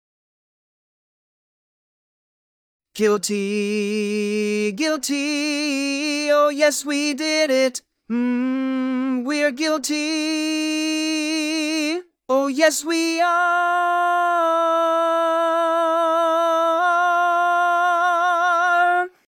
Key written in: D♭ Major
Type: Barbershop
Each recording below is single part only.